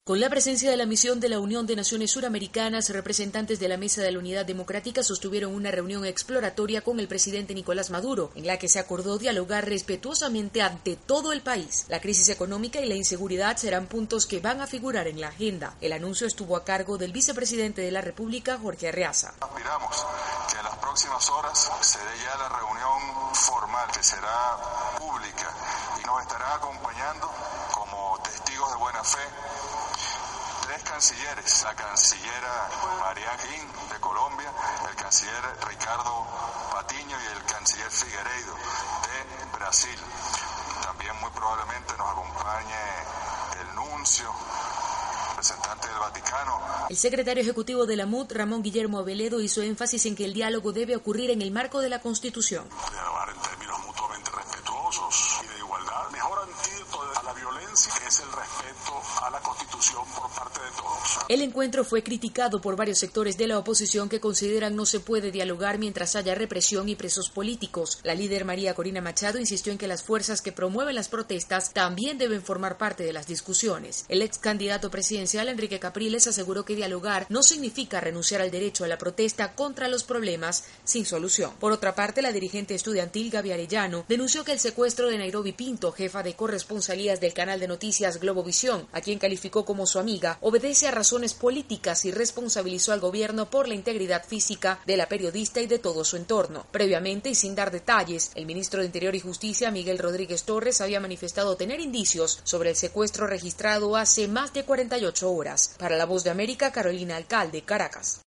corresponsal de la VOZ de América, informa desde Caracas.